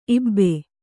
♪ ibbe